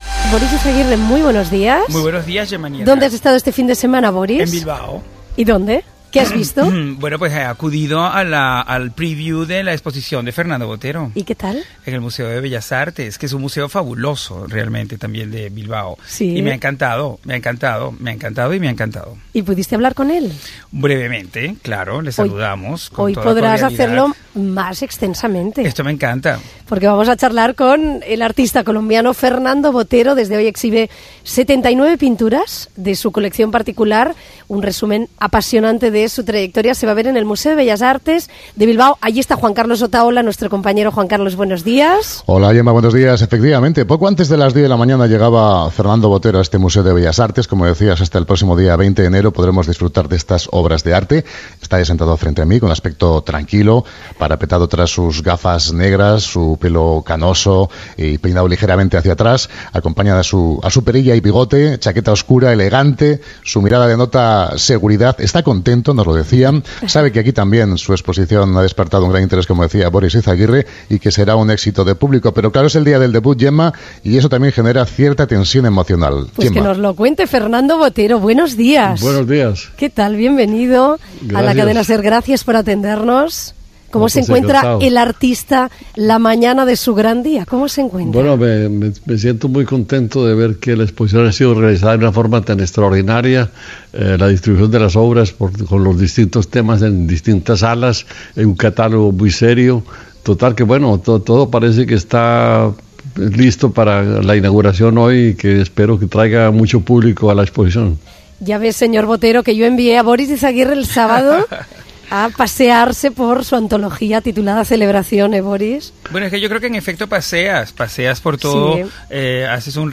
Entrevista a l'escultori i pintor colombià Fernando Botero amb motiu de la seva exposició retrospectiva "Celebración" al Museo de Bellas Artes de Bilbao